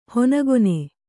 ♪ honagone